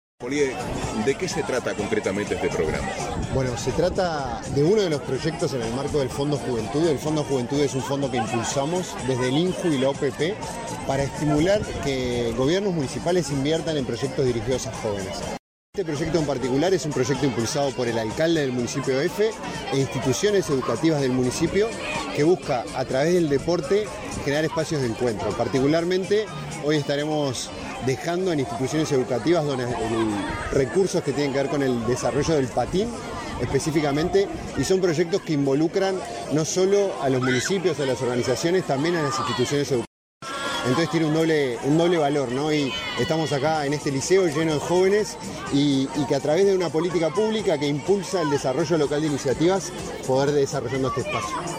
Declaraciones a la prensa del director del INJU, Felipe Paullier
El Instituto Nacional de la Juventud (INJU), el Área de Descentralización de la Oficina de Planeamiento y Presupuesto (OPP) y el Municipio F de Montevideo, lanzaron, este 9 de agosto, el programa Creciendo Con Vos Nos Incluimos, financiado a través del Fondo Juventud. Tras el evento, el director del INJU, Felipe Paullier, realizó declaraciones a la prensa.